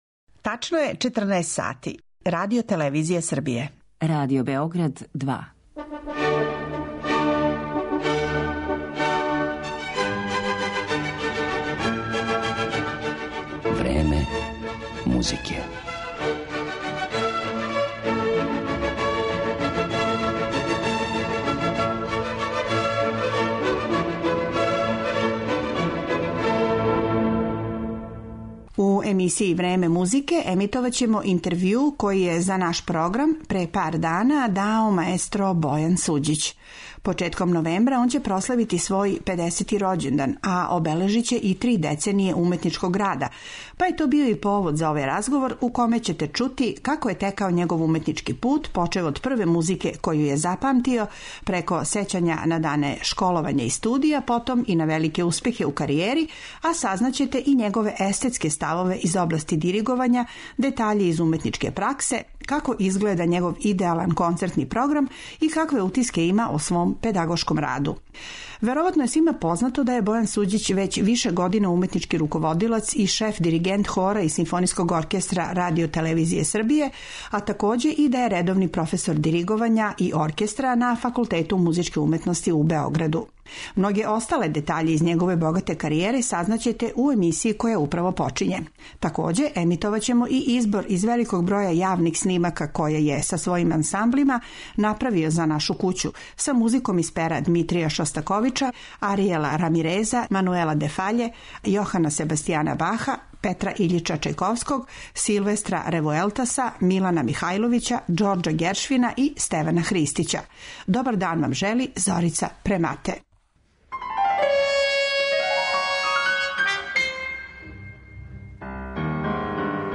У емисији ће бити емитовани и снимци диригентских успеха маестра Суђића.